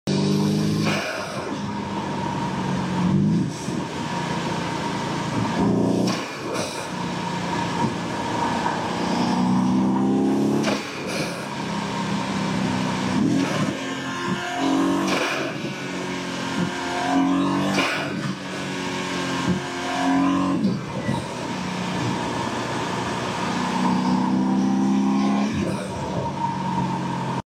Cls63 💀 no music needed sound effects free download